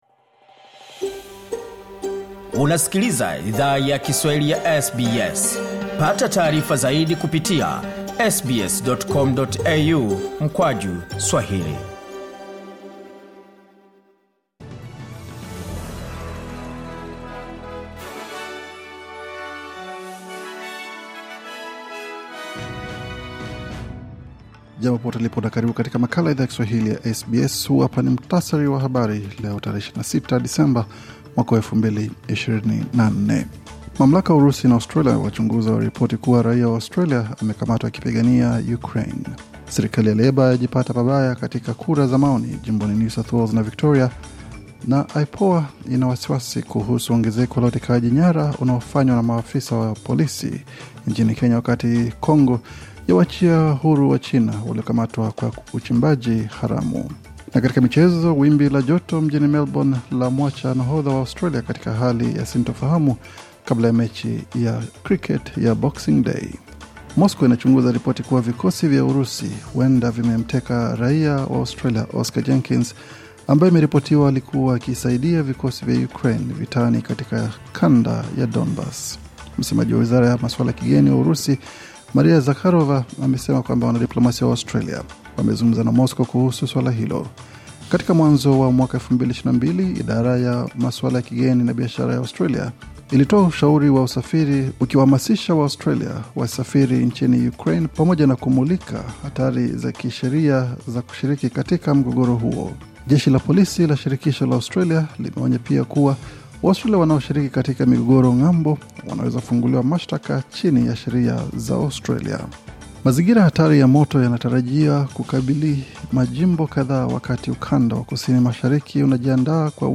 Taarifa ya Habari 26 Disemba 2024